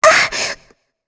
Shea-Vox_Damage_kr_01.wav